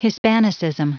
Prononciation du mot : hispanicism